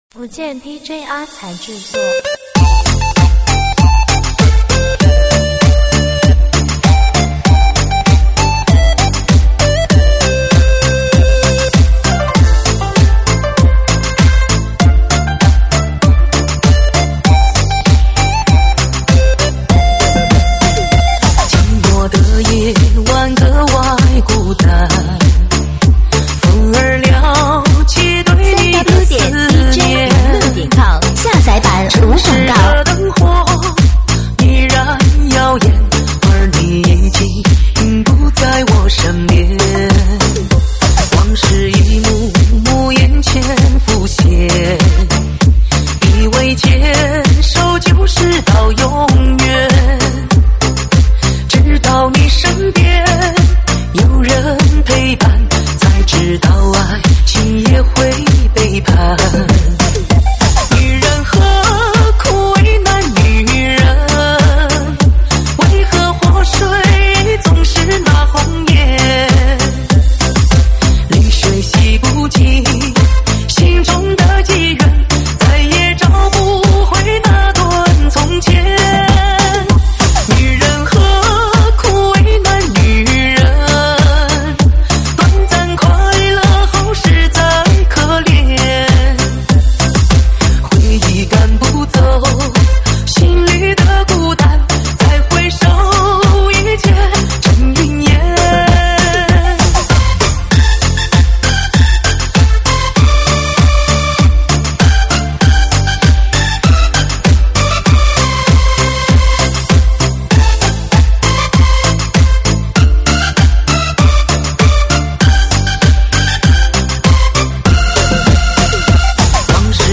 舞曲类别：吉特巴